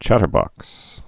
(chătər-bŏks)